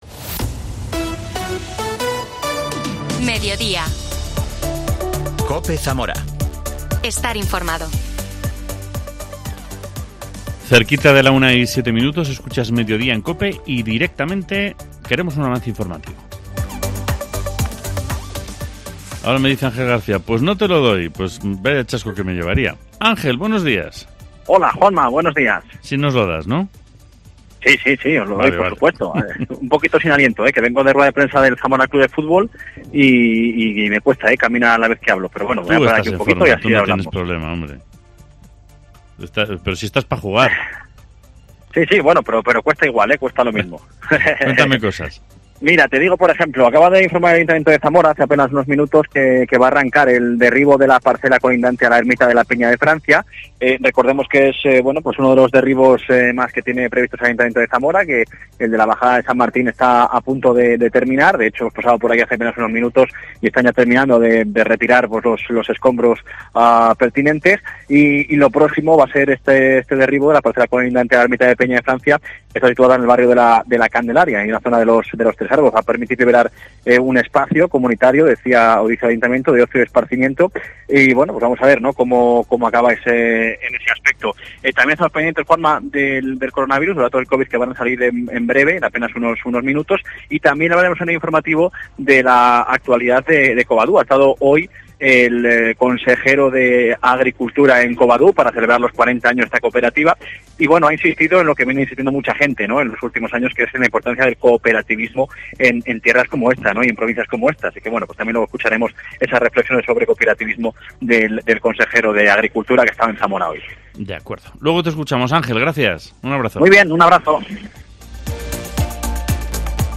AUDIO: Los diputados del PSOE y del PP por Zamora, Antidio Fagúndez y Elvira Velasco, valoran los PGE para 2023.